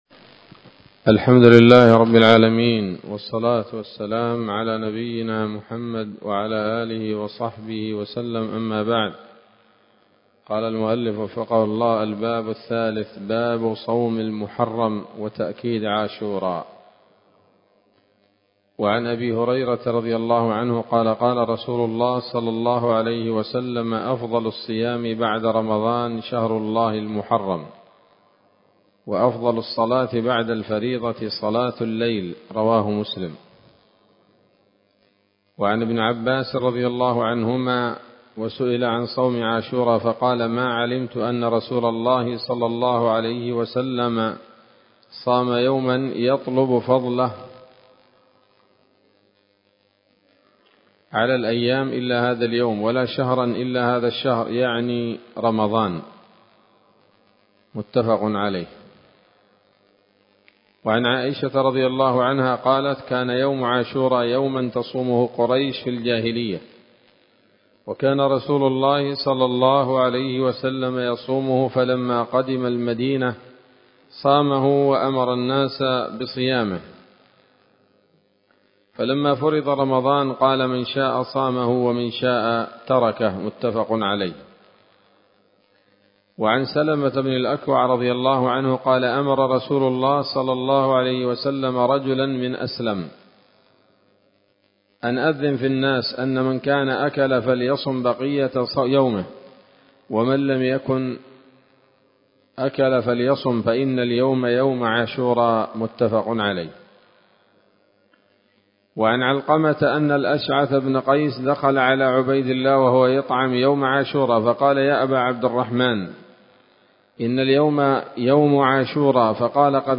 الدرس الثاني والعشرون من كتاب الصيام من نثر الأزهار في ترتيب وتهذيب واختصار نيل الأوطار